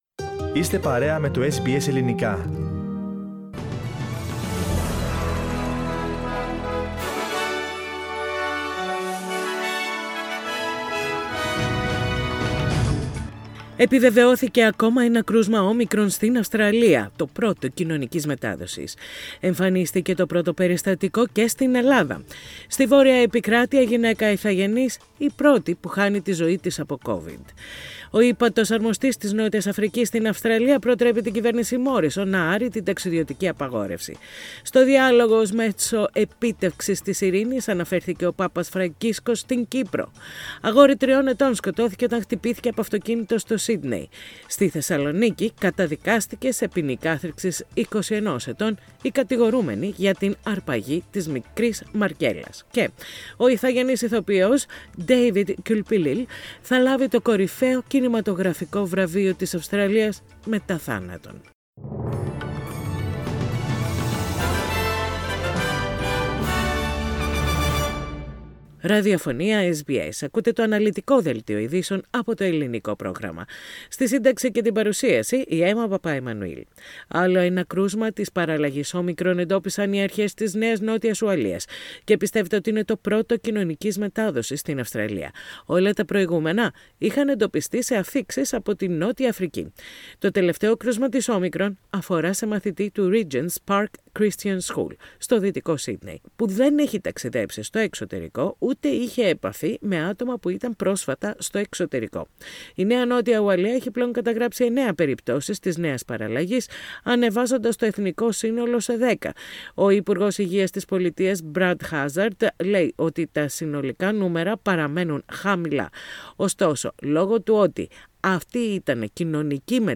News in Greek. Source: SBS Radio